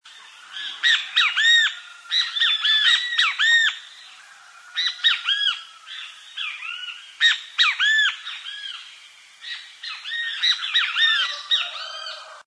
bem-te-vi, no estacionamento do Paulista é fácil ouvir o
Todos sons do centro da cidade de Ubatuba.